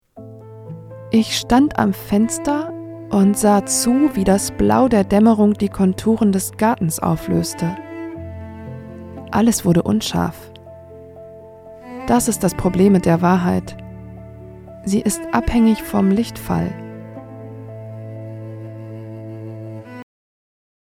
markant
Mittel minus (25-45)
Russian, Eastern European
Audio Drama (Hörspiel), Audiobook (Hörbuch)